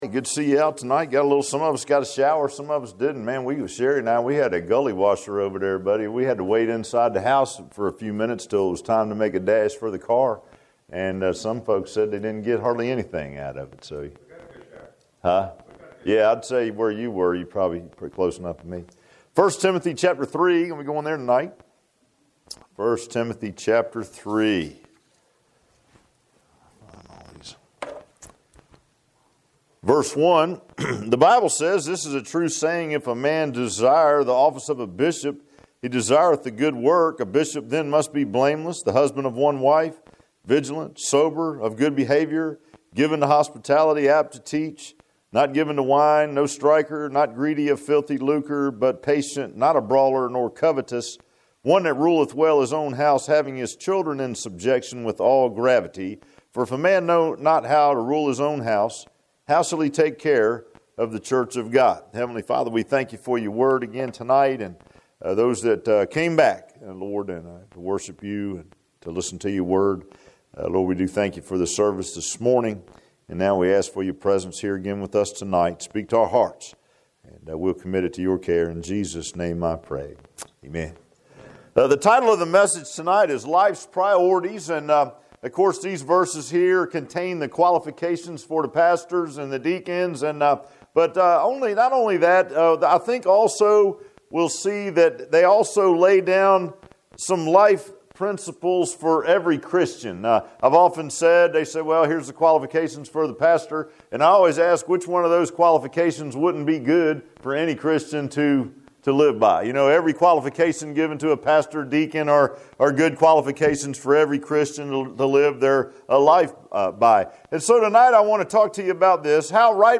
I Timothy 3:1-5 Service Type: Sunday PM Bible Text